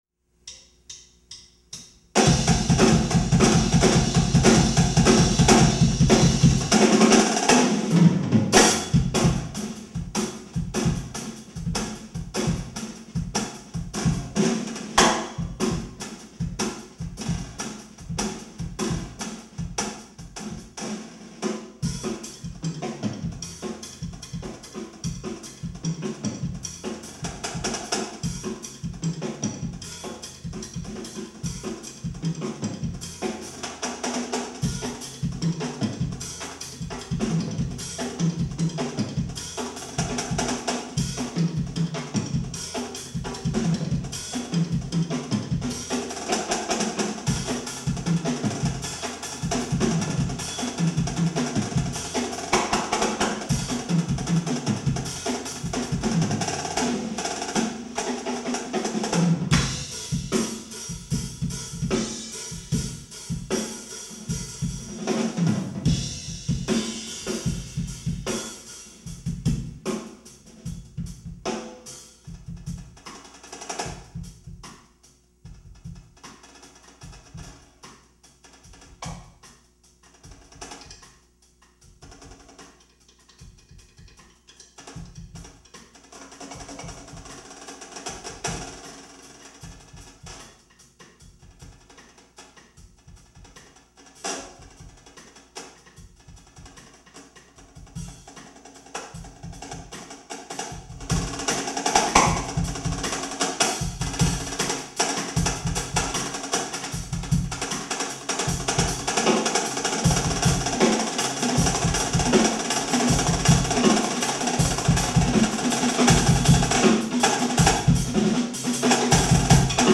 duet for marching snare and drumset